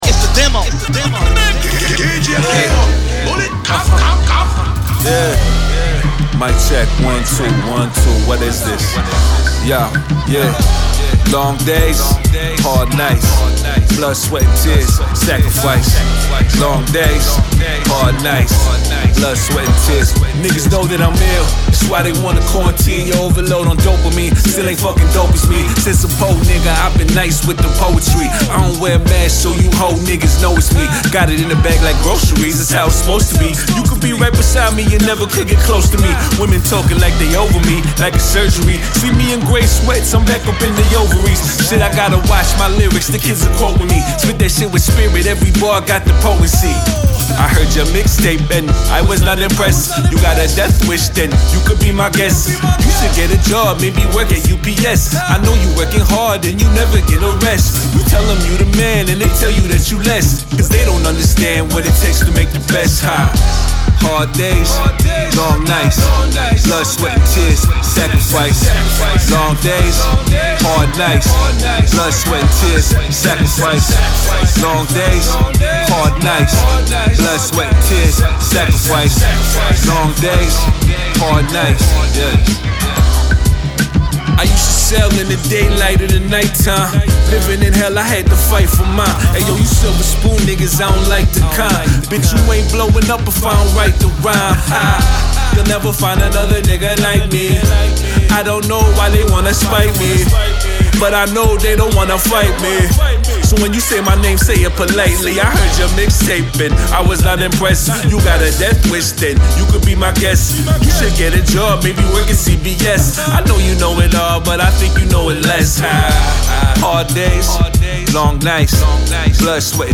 Description : Classic Canadian Hip Hop.